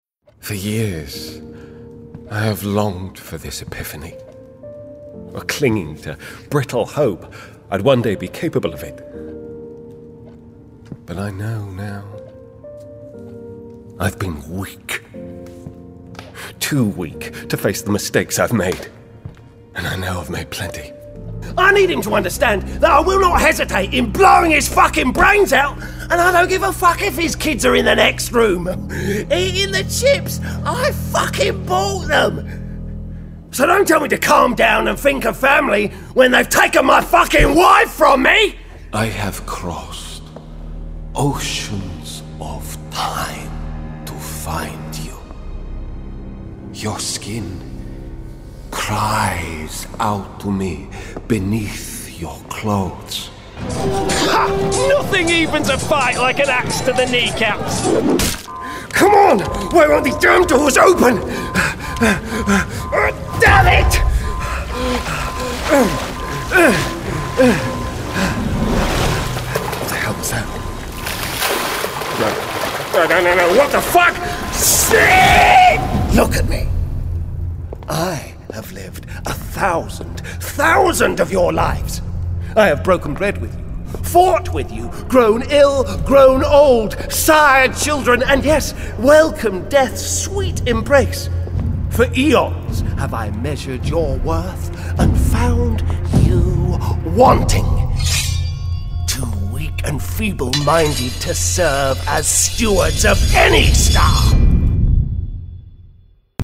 Gaming Reel
accented, accented English, animated, biting, british, character, conversational, edgy, gritty, ominous, real, tough
British